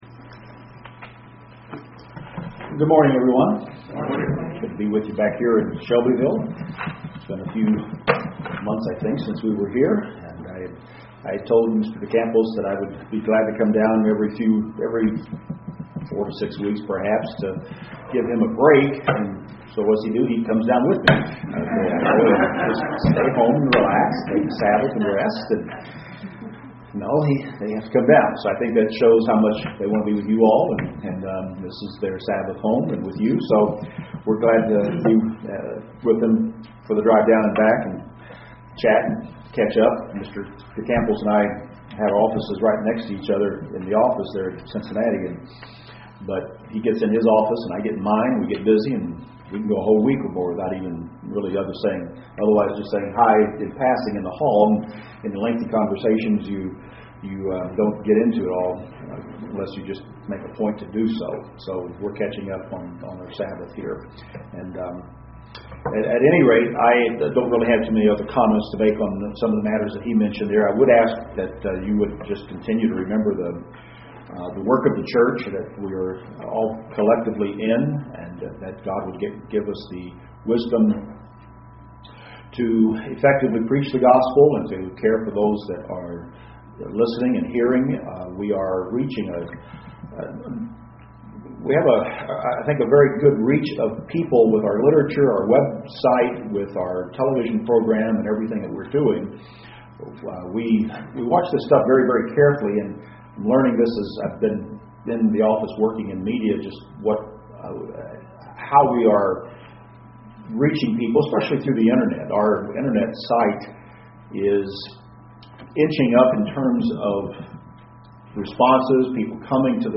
Three lessons of trust from the book of Philemon UCG Sermon Transcript This transcript was generated by AI and may contain errors.